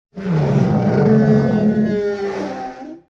Dinosaur Roaring Sound
horror
Dinosaur Roaring